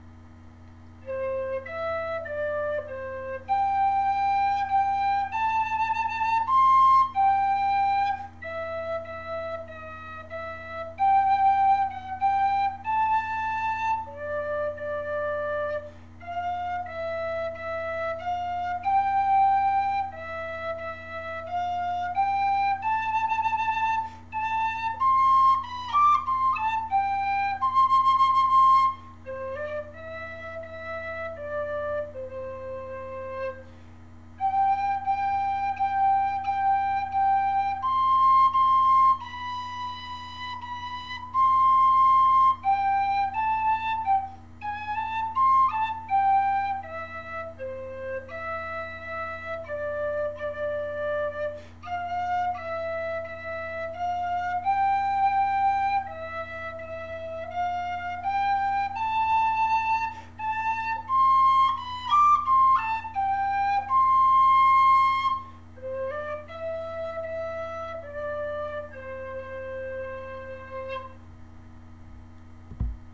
melody on the Pennywhistle
Hans_älskande_hjerta_C_whistle.ogg